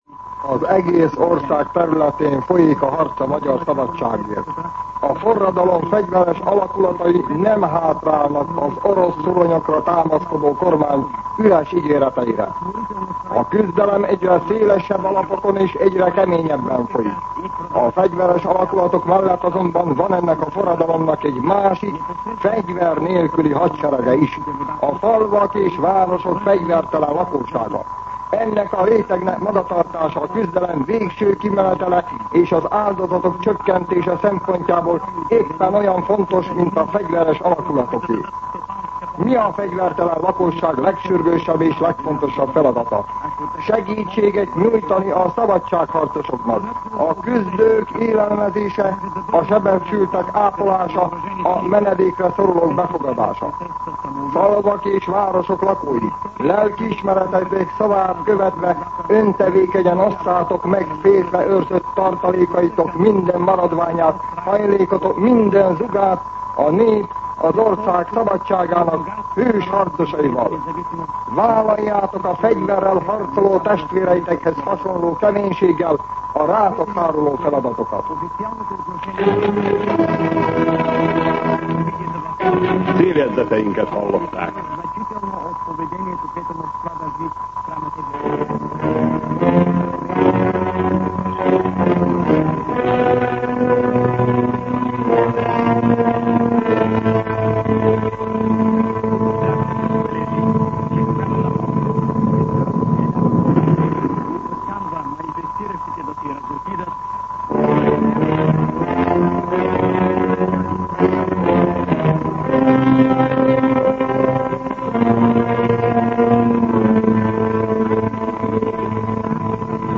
MűsorkategóriaKommentár
Megjegyzéshosszú zene a végén - ezért hosszabb